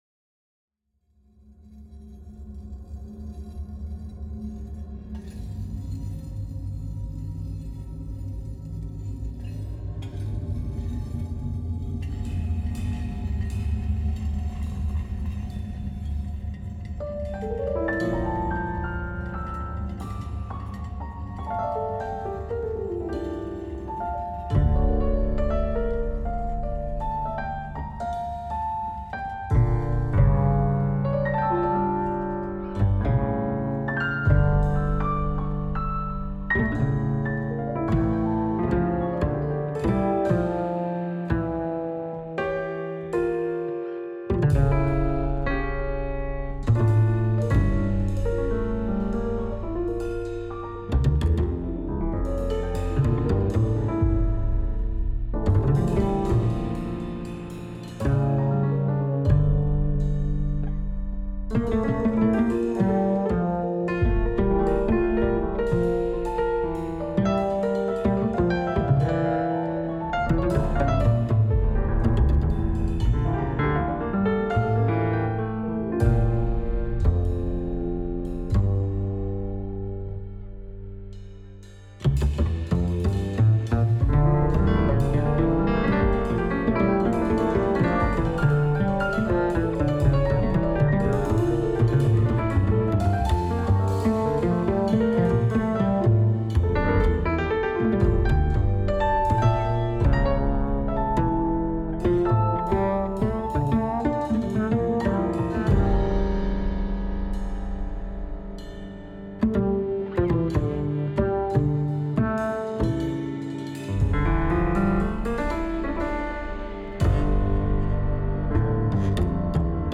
Composition pour: Piano, Contrebasse, Batterie, Guitare à l'archet électronique, Bruits comme dans un bunker.
Avec le texte - Composition pour: Piano, Contrebasse électrique, Batterie, Guitare jouée à l'archet électronique, Bruits d'ambiance: comme dans un bunker.